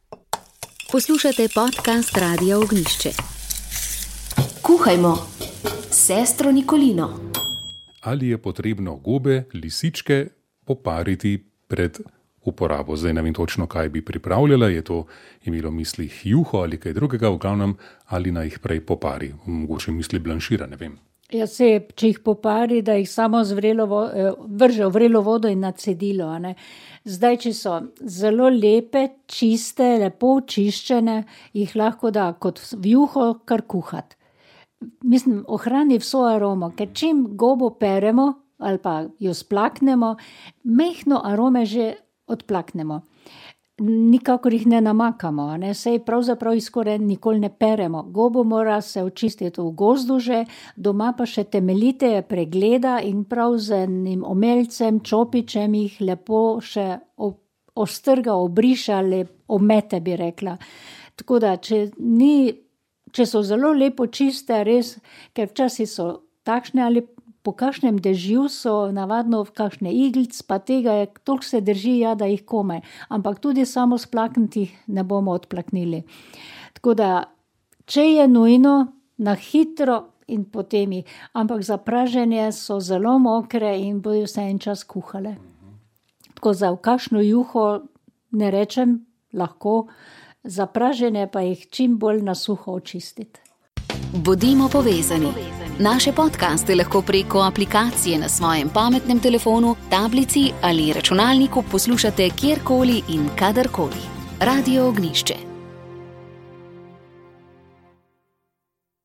Duhovni nagovor
Duhovni nagovor je pripravil novomeški škof Andrej Glavan.